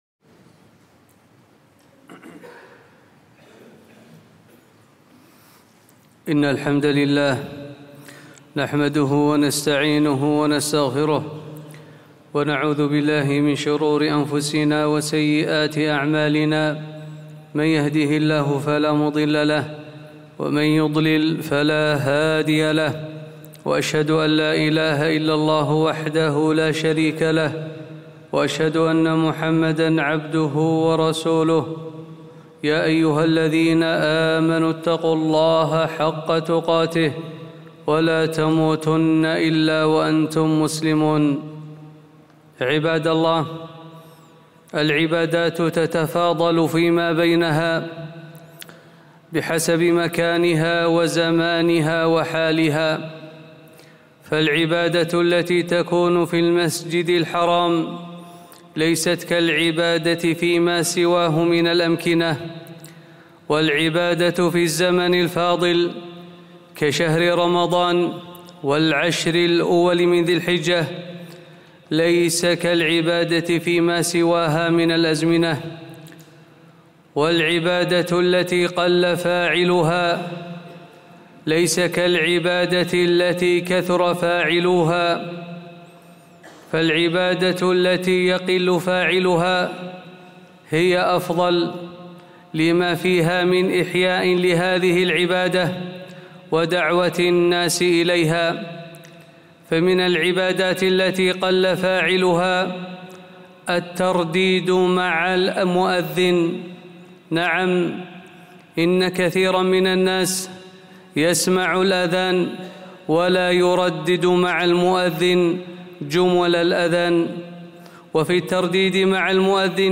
خطبة - ( إذا سمعتم المؤذن فقولوا مثل ما يقول)